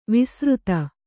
♪ visřta